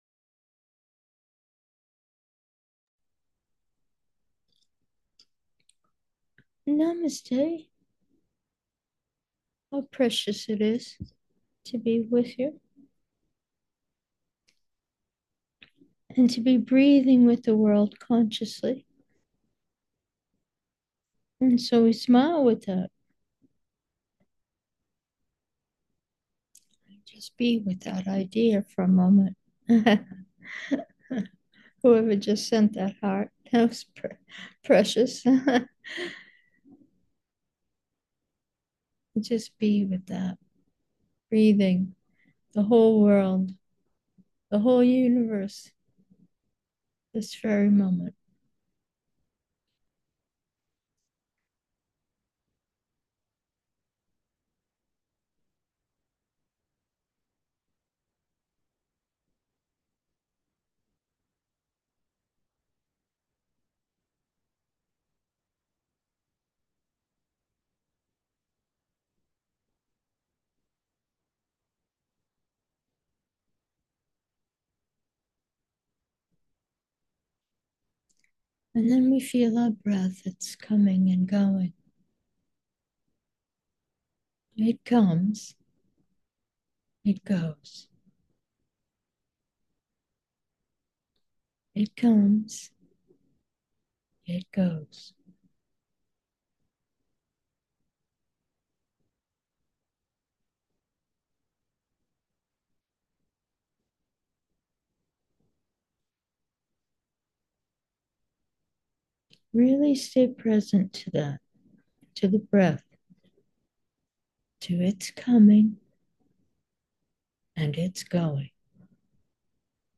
Meditation: come and go 1